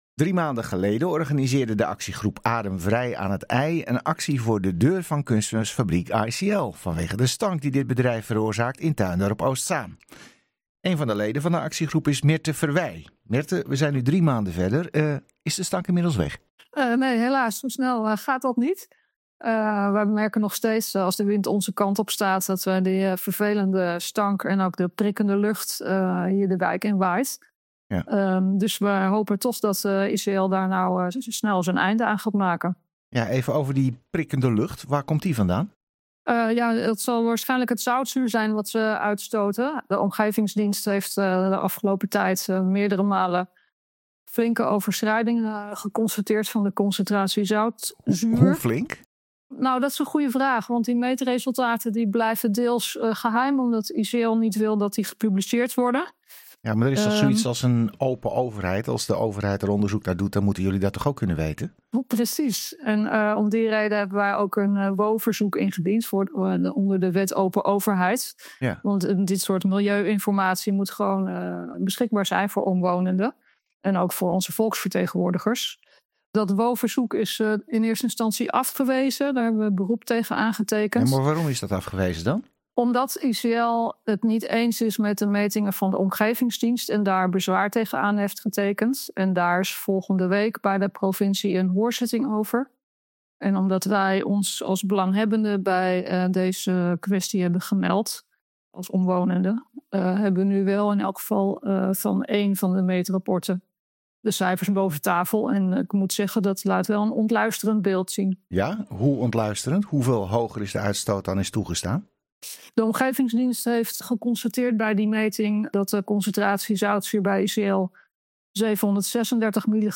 Radio Boven IJ sprak met Adem Vrij aan het IJ. De bewonersorganisatie kreeg schokkende resultaten van een meting van de Omgevingsdienst onder ogen over kunstmestfabriek ICL.